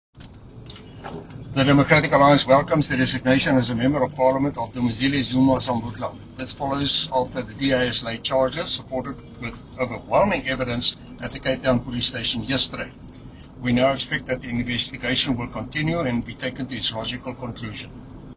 Issued by Chris Hattingh MP – DA Spokesperson on Defence and Military Veterans
Afrikaans soundbite by Chris Hattingh MP.